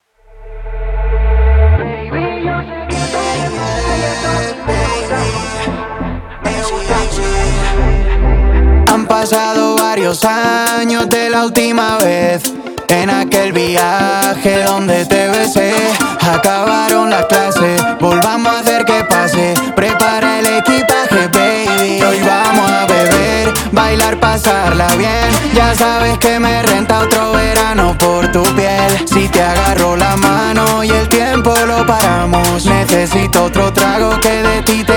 Скачать припев
Latin